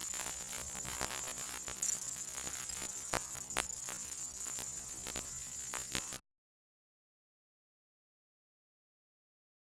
Shock low.wav